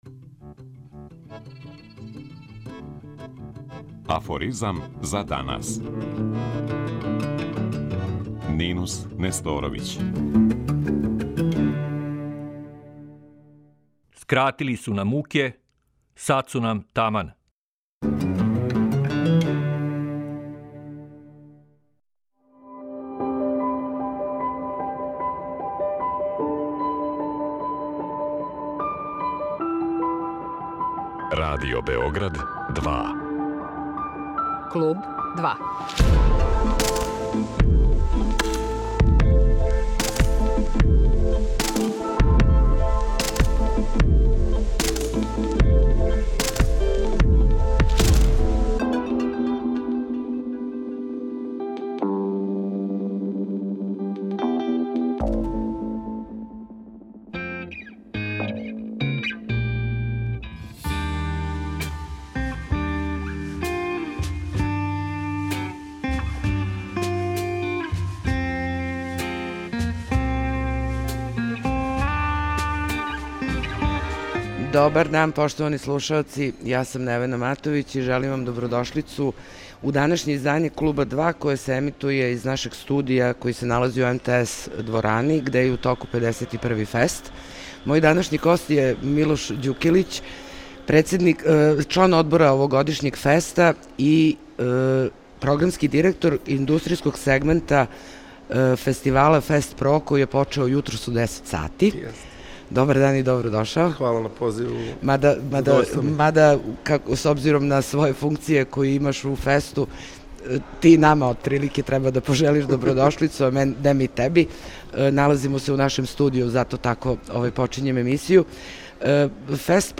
Данашње издање емисије емитује се из нашег студија који се налази у МТС Дворани где је у току 51. ФЕСТ.